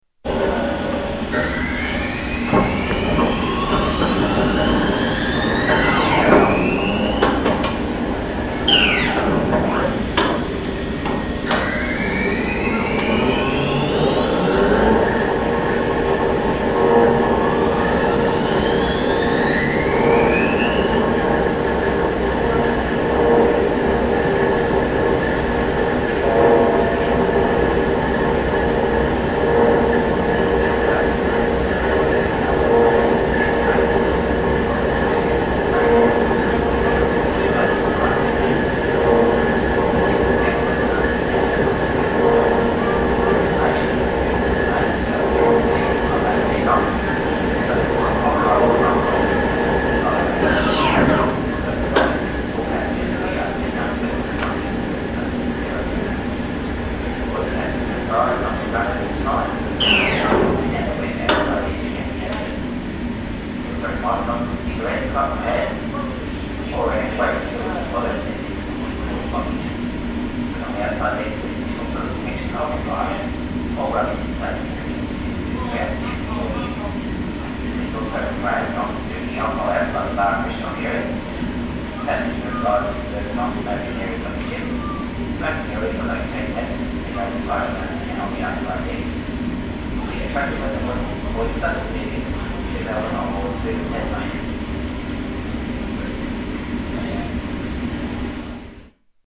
Station Pier Melbourne